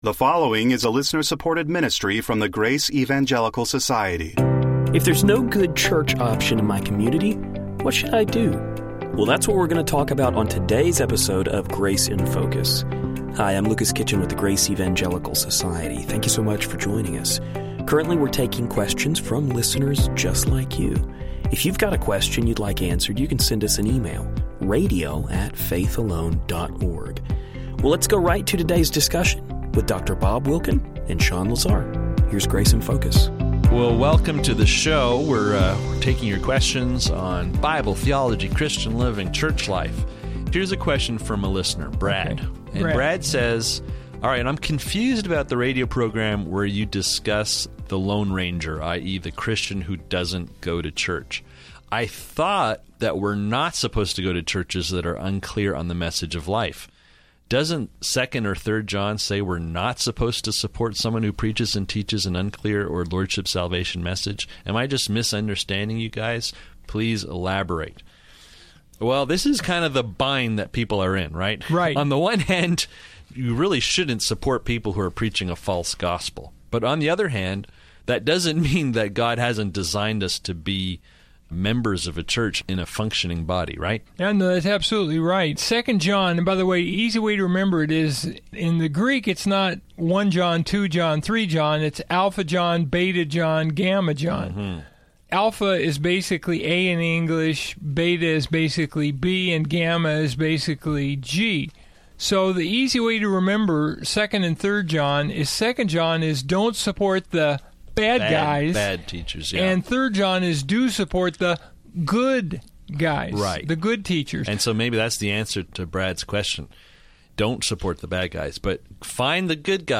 Specifically, we will hear the guys discuss church attendance. What should a believer do when there aren’t any doctrinally sound churches near? Should they stop going to church?